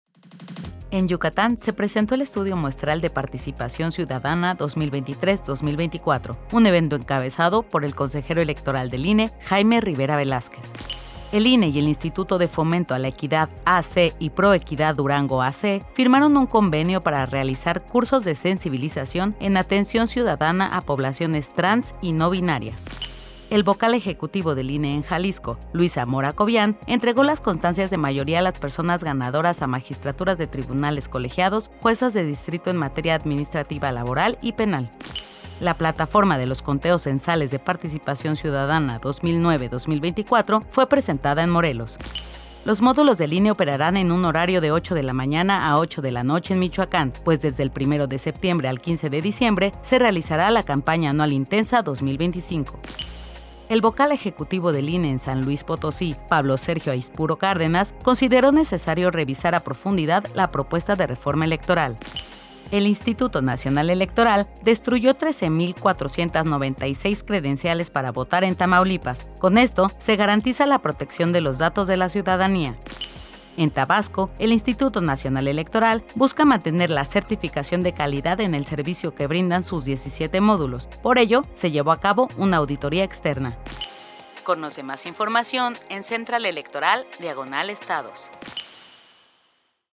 Cápsula de audio con los acontecimientos más relevante del INE en las 32 entidades de la República, del 6 al 12 de agosto de 2025